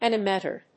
音節an・i・mad・vert 発音記号・読み方
/`ænəmædvˈɚːt(米国英語), anɪmədˈvəːt(英国英語)/